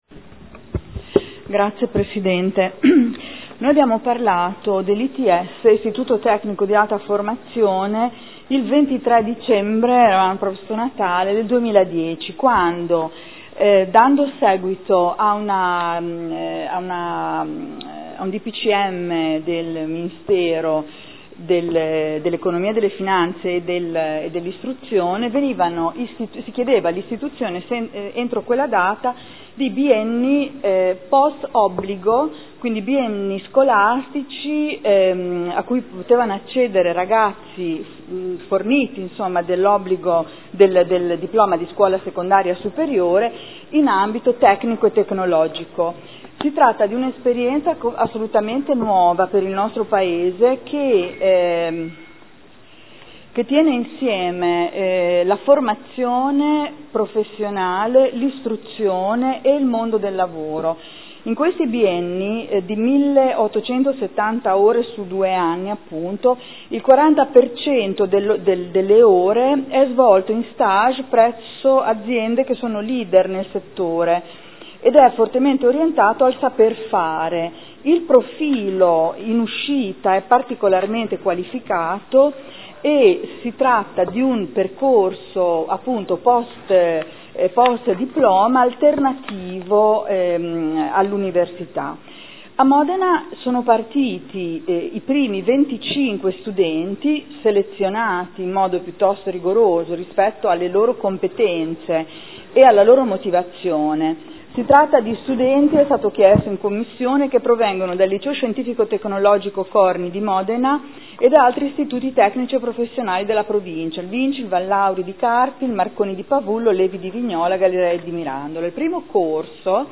Seduta del 12/09/2013 Adesione del Comune di Modena, in qualità di socio fondatore, alla Fondazione Istituto Tecnico superiore Meccanica, Meccatronica, Motoristica, Packaging